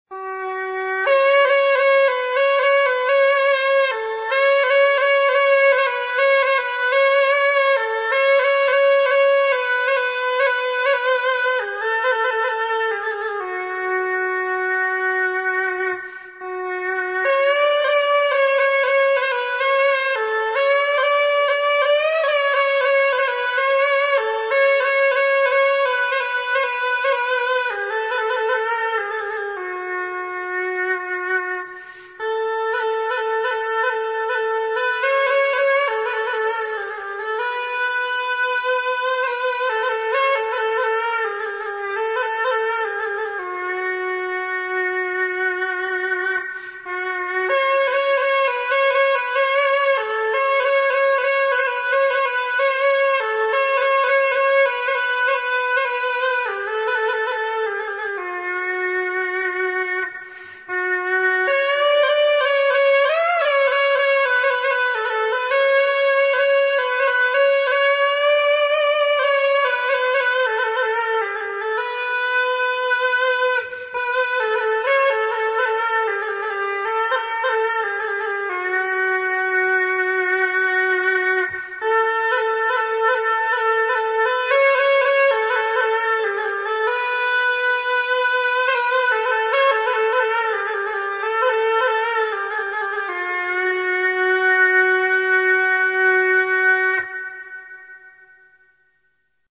Камыс-сырнай